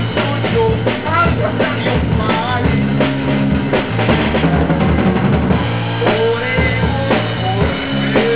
El grup està format per bateria, baix, guitarra i veu.